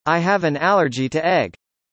音節：al・ler・gy
発音記号：ˈælɚdʒi
カタカナ読みでは「アレジィ」です。
「gy」を「ギー」とは発音しません！